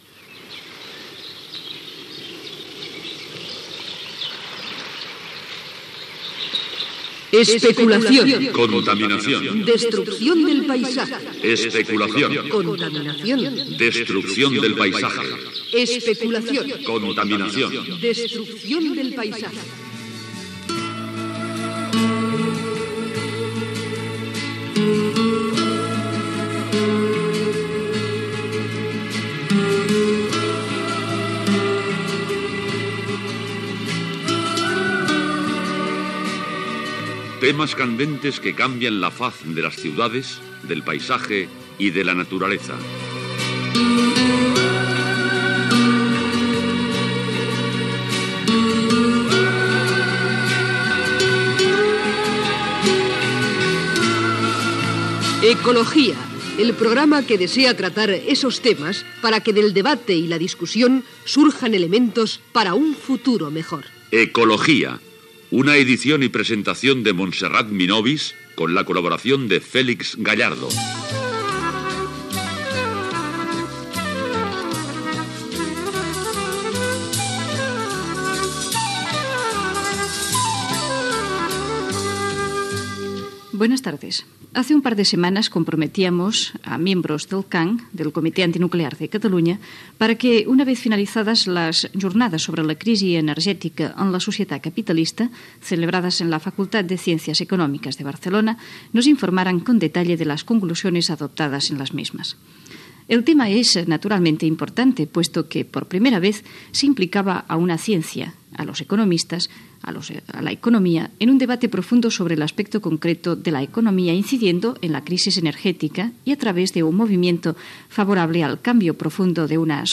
Careta del programa
protestes contra la instal·lació de la factoria de la General Motors a Saragossa, Jornades sobre la crisis energètica a la societat capitalista fetes a Barcelona. Gènere radiofònic Informatiu